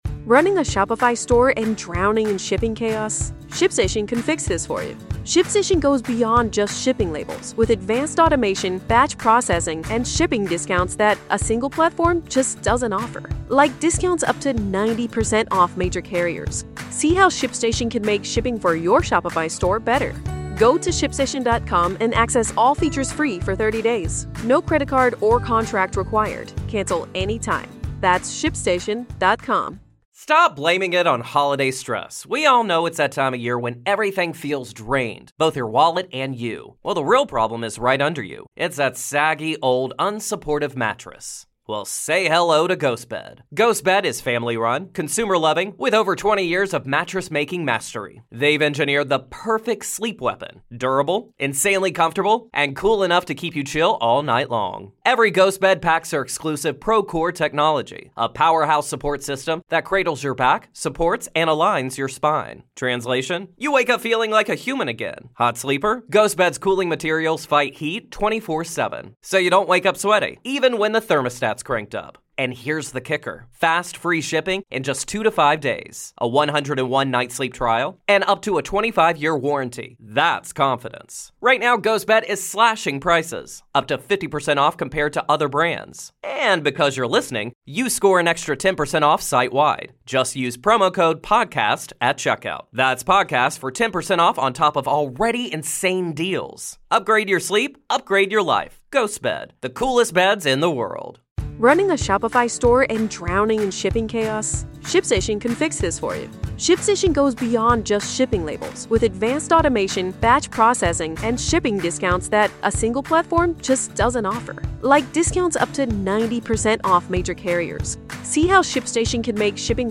A Life of Hauntings | A Conversation